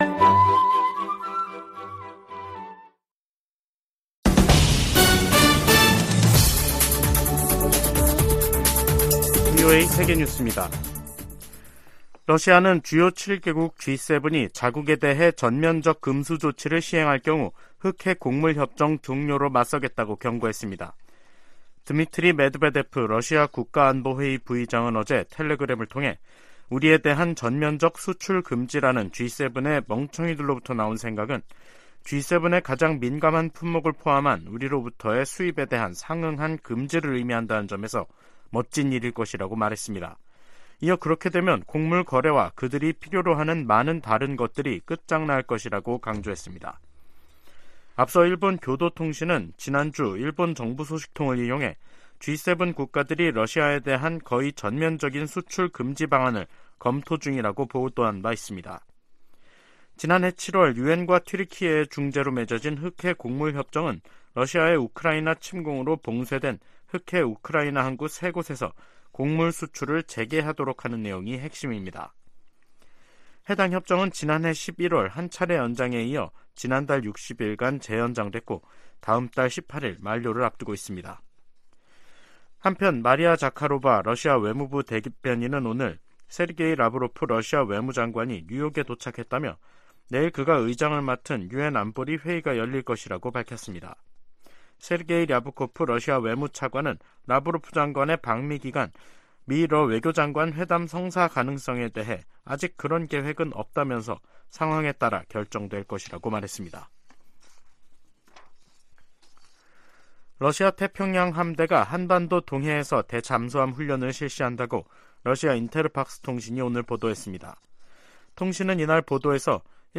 VOA 한국어 간판 뉴스 프로그램 '뉴스 투데이', 2023년 4월 24일 2부 방송입니다. 백악관은 윤석열 한국 대통령의 국빈 방문이 미한 관계의 중요성을 증명하는 것이라고 강조했습니다. 윤 대통령은 방미를 앞두고 워싱턴포스트 인터뷰에서 미한동맹의 중요성을 거듭 강조했습니다. 미 국무부가 핵보유국 지위와 관련한 북한의 주장에 대해 불안정을 조성하는 행동을 자제하고 협상에 복귀할 것을 촉구했습니다.